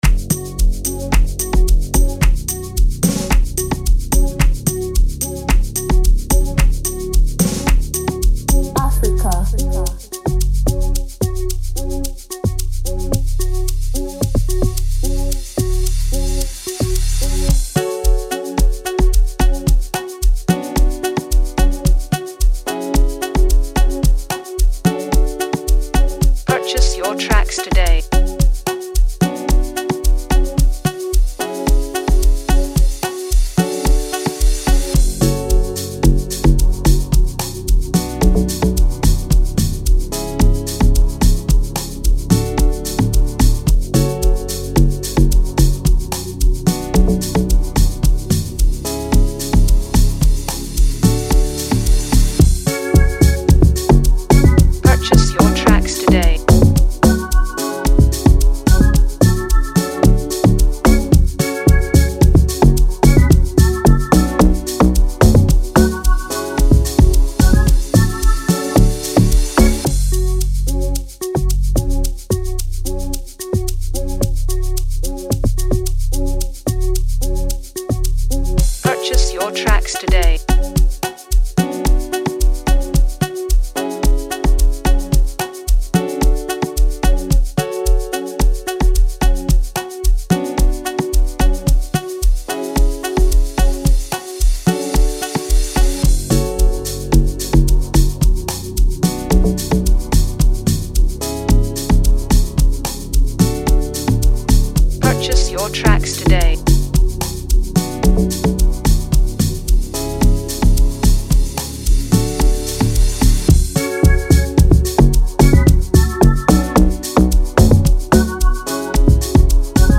a scorching Amapiano instrumental